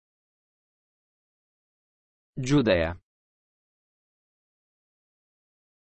Judea އަށް ލެޓިނުން ކިޔަނީ ޖޫދަޔާ އެވެ.